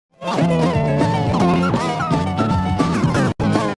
VHS Glitch Audio-Video Effect